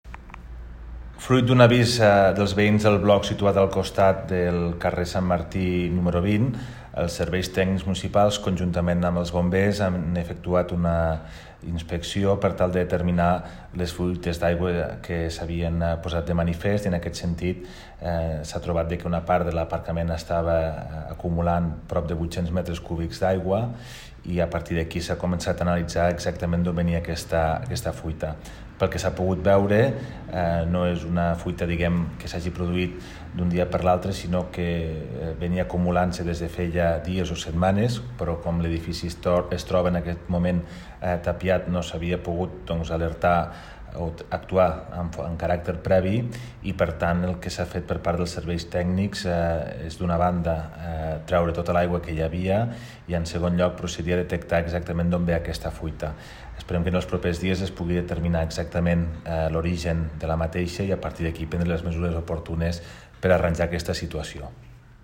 Compartir Facebook Twitter Whatsapp Descarregar ODT Imprimir Tornar a notícies Fitxers relacionats Tall de veu del primer tinent d'alcalde Toni Postius, sobre la inundació en un immoble del c. Sant Martí (1.0 MB) T'ha estat útil aquesta pàgina?
tall-de-veu-del-primer-tinent-dalcalde-toni-postius-sobre-la-inundacio-en-un-immoble-del-c-sant-marti